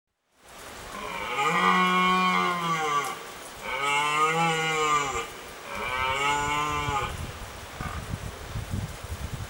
На этой странице собраны натуральные звуки оленей: от нежного фырканья до мощного рева в брачный период.
Шум оленя под дождем в лесу